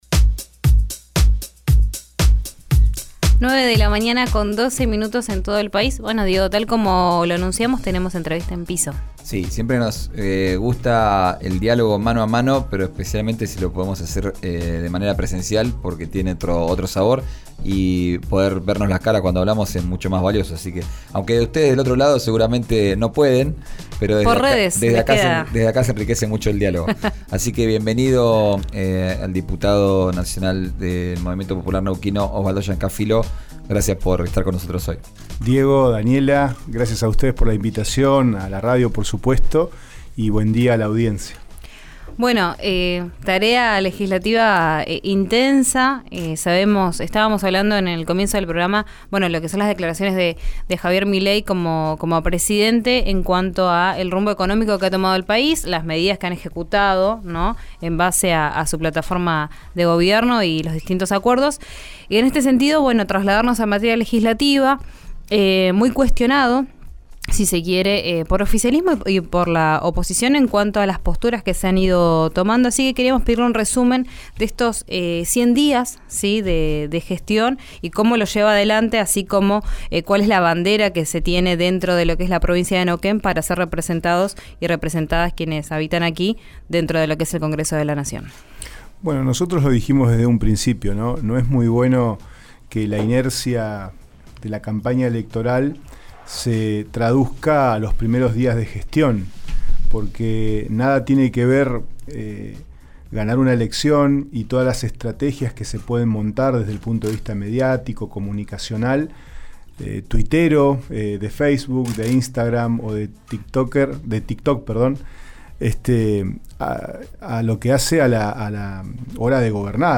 Escuchá al diputado nacional del MPN, Osvaldo Llancafilo, en RÍO NEGRO RADIO: